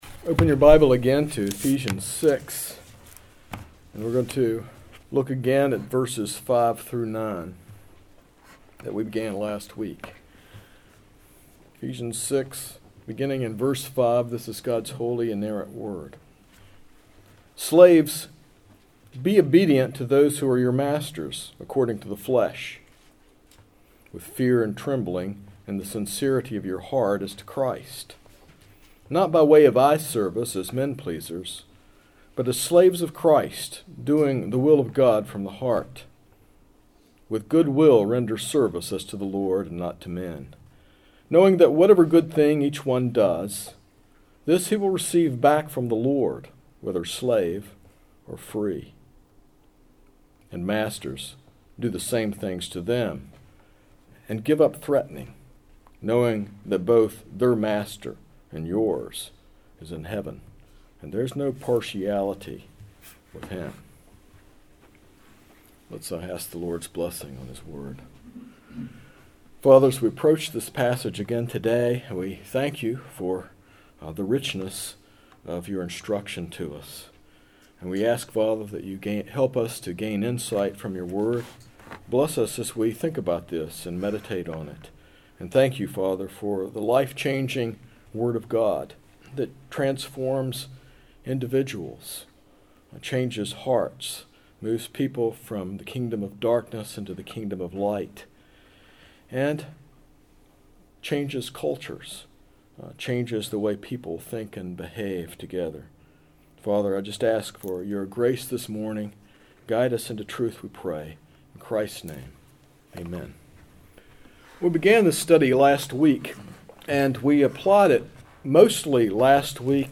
This sermon explores how a Christian understanding of vocation transforms our perspective on work. It emphasizes that all work is valuable to God, helps us correctly identify ourselves in Christ rather than our jobs, and protects us from common workplace temptations.